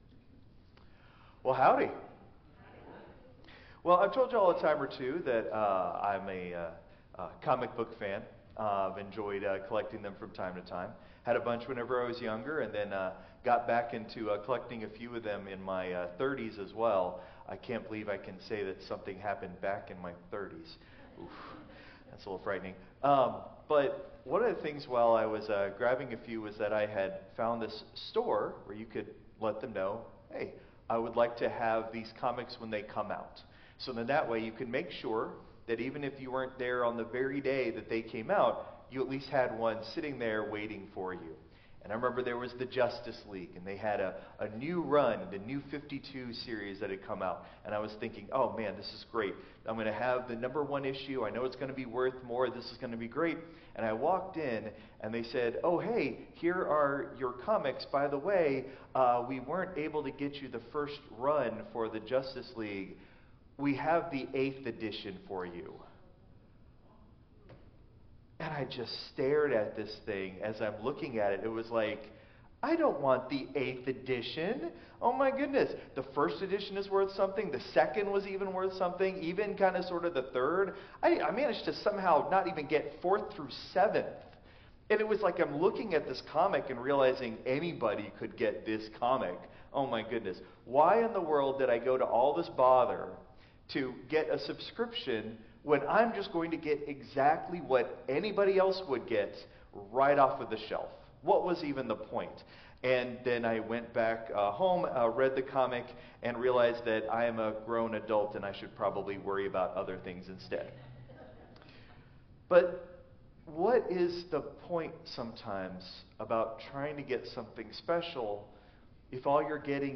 Christ Memorial Lutheran Church - Houston TX - CMLC 2025-02-23 Sermon (Traditional)